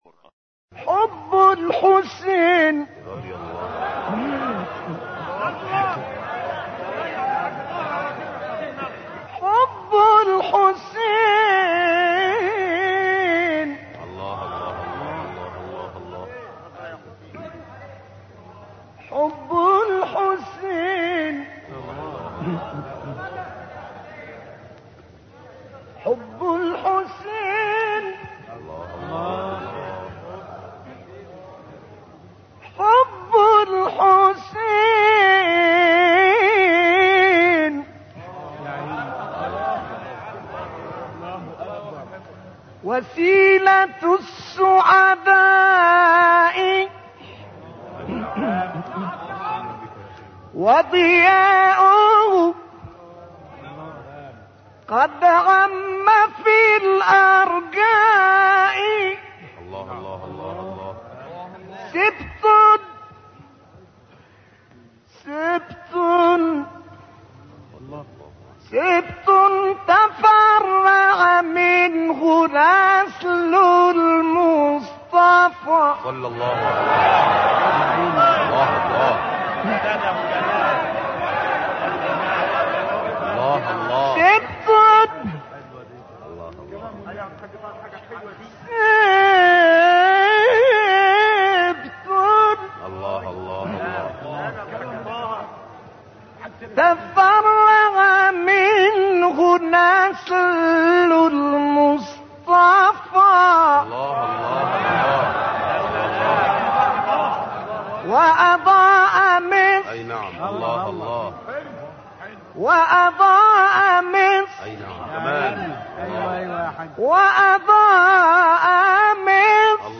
متخصصان فن تلاوت، شیخ طه الفشنی را دارای صدایی جذاب و ملکوتی خوانده‌اند که توانایی حرکت بین 17 مقام موسیقی را به شکل لطیف و نادری اجرا می‌کرده است.
در ادامه ابتهال فوق‌العاده‌ای با عنوان «حب الحسین (ع)» با صدای شیخ طه الفشنی آمده است.